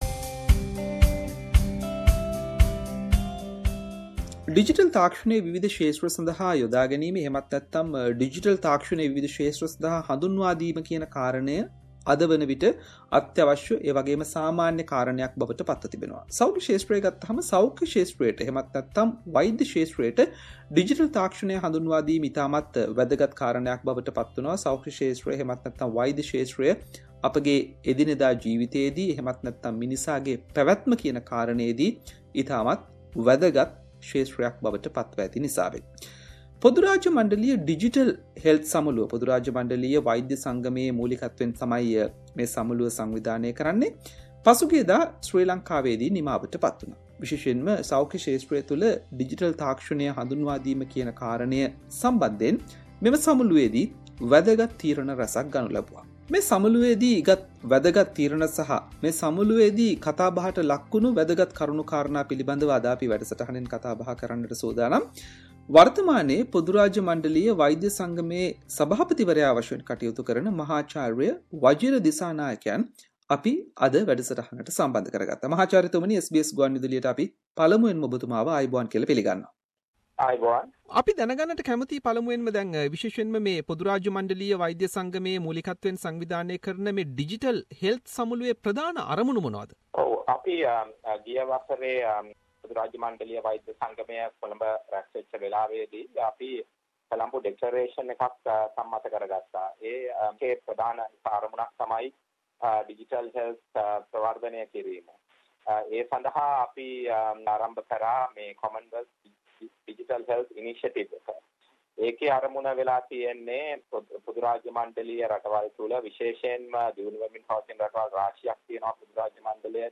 In this conference digital health experts discussed important factors related implementing digital technology in to health sector. SBS Sinhalese interviewed